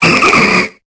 Cri d'Okéoké dans Pokémon Épée et Bouclier.